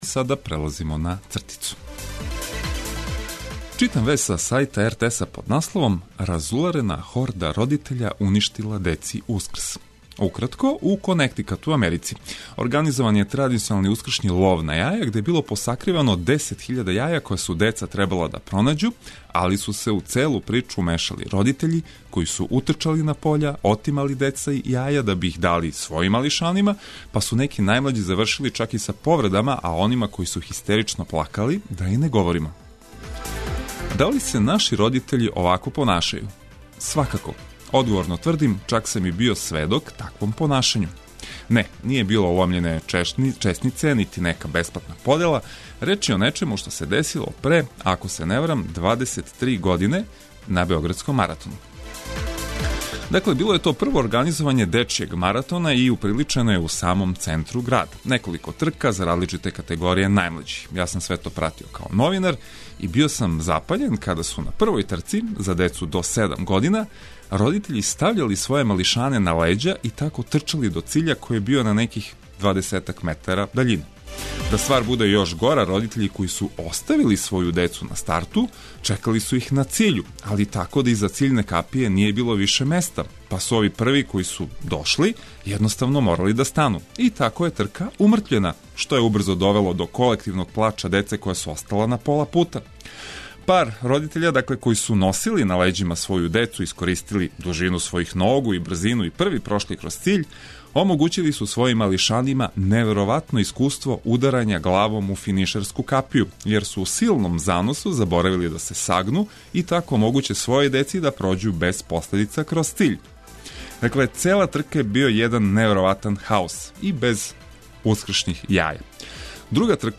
Разне теме овог јутра, уз праву музику за буђење!